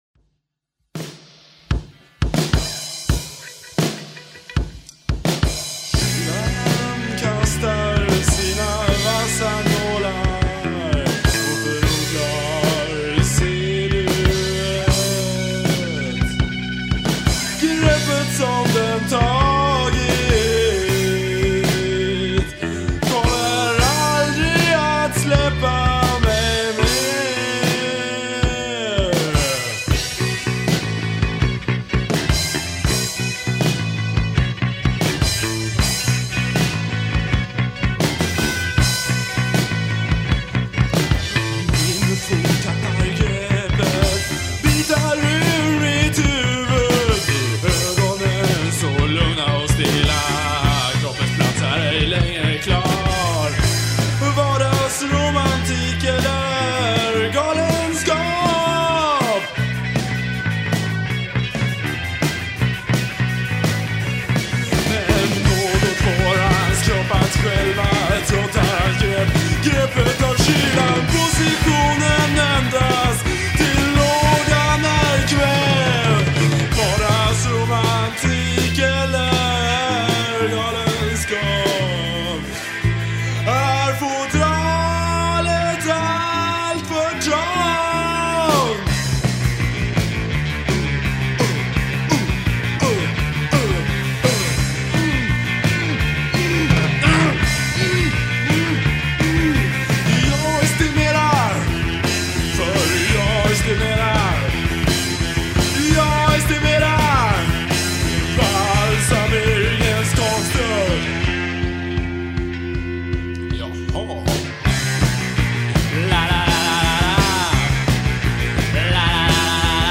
Bass, b-Stimme
Gitarre, b-Stimme
Trommel